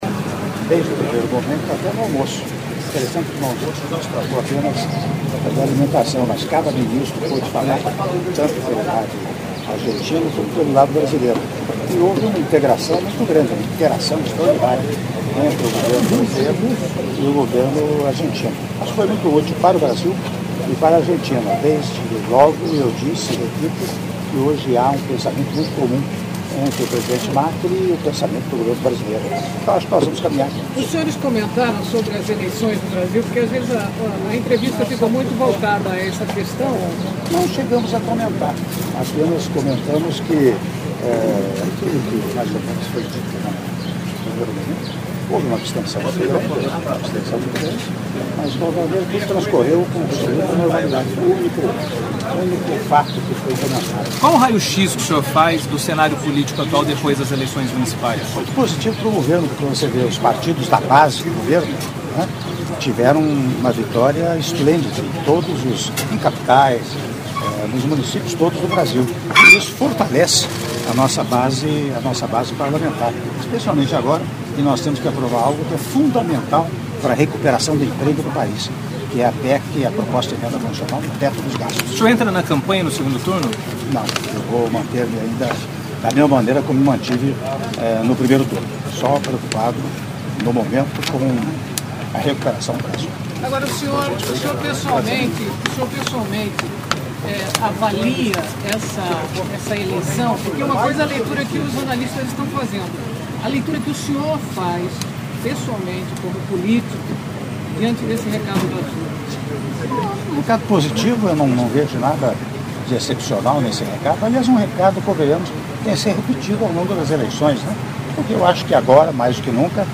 Áudio da entrevista coletiva concedida pelo presidente da República, Michel Temer, no aeroporto de Buenos Aires - Buenos Aires/Argentina (02min20s)